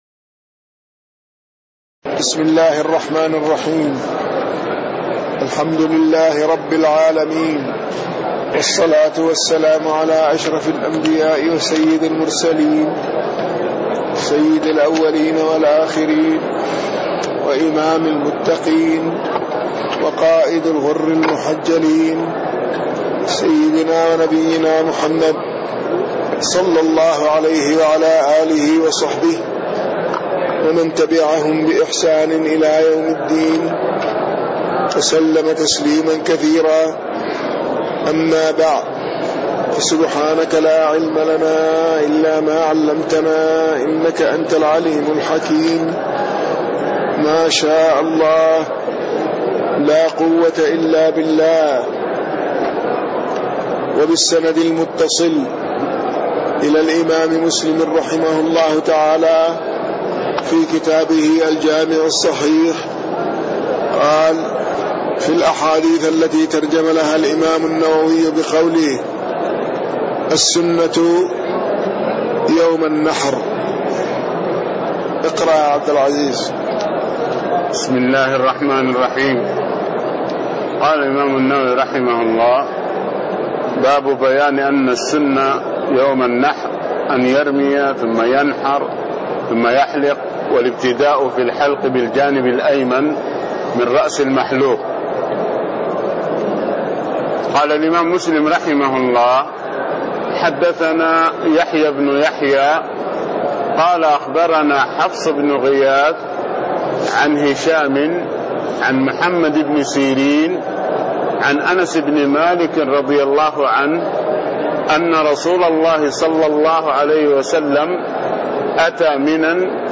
تاريخ النشر ٩ ربيع الأول ١٤٣٤ هـ المكان: المسجد النبوي الشيخ